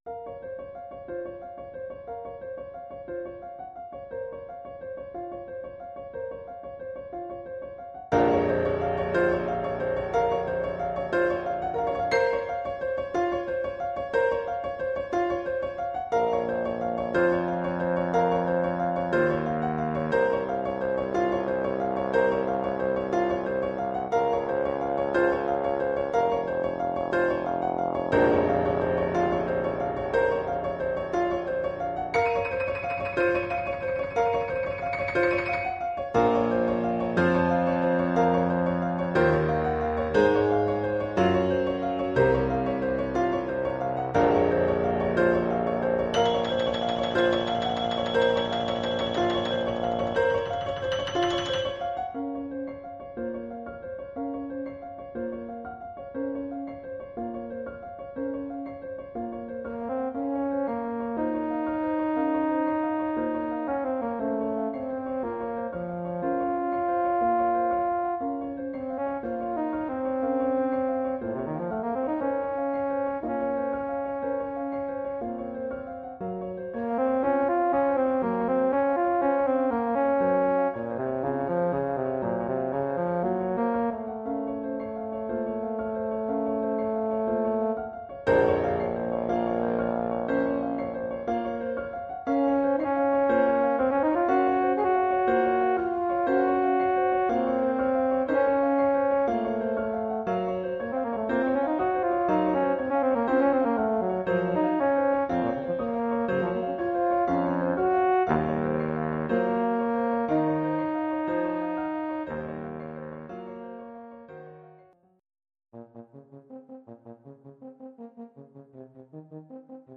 Oeuvre pour saxhorn / euphonium